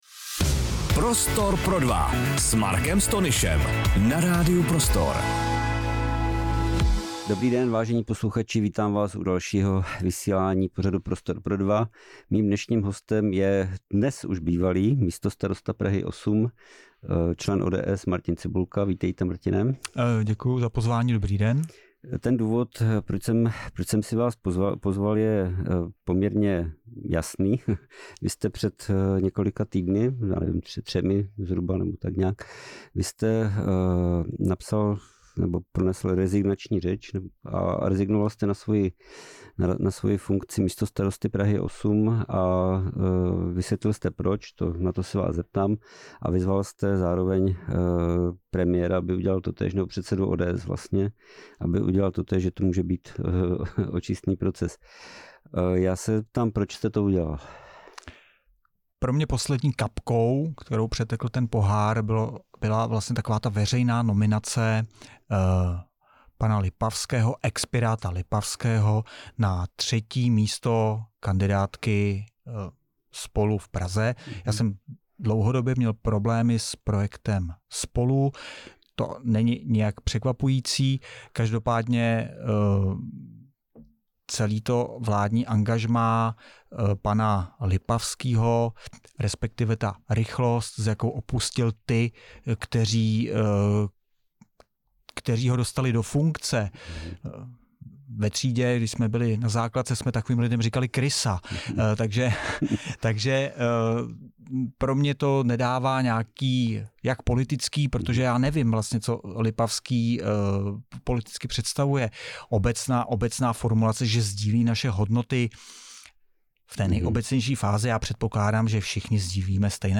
Hostem pořadu Prostor pro dva byl spoluzakládající člen Občanské demokratické strany a dlouholetý místostarosta Prahy 8 Martin Cibulka.